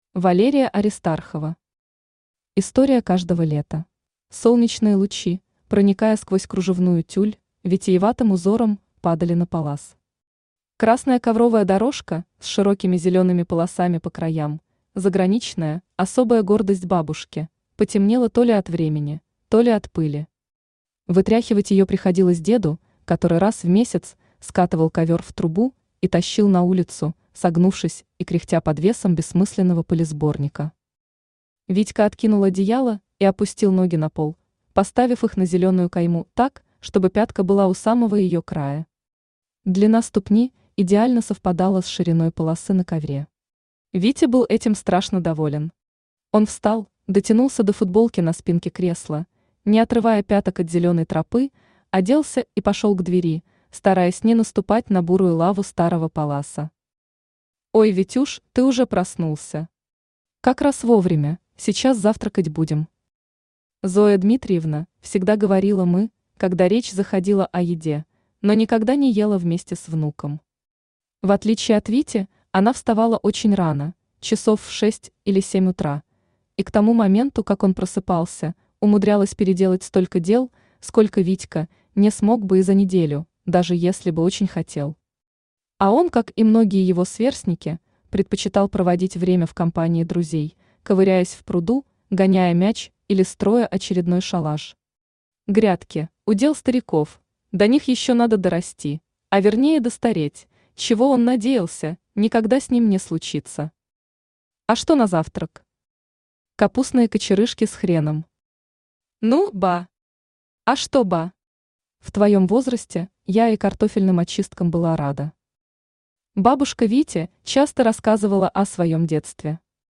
Аудиокнига История каждого лета | Библиотека аудиокниг